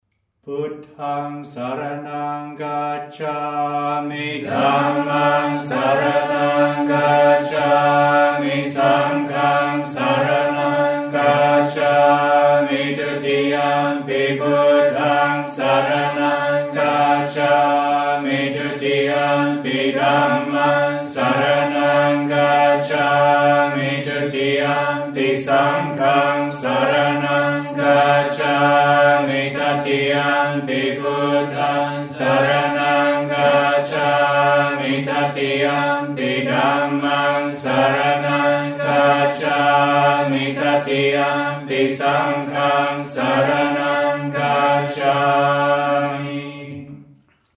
» Pali-English Chanting